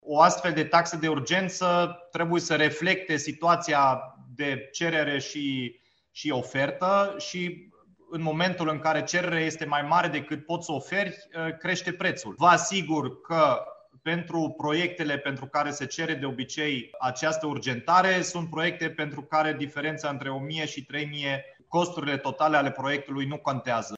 Primarul Dominic Fritz a explicat motivul pentru care se impune o triplare a taxei de urgentă pentru eliberarea unui certificat de urbanism: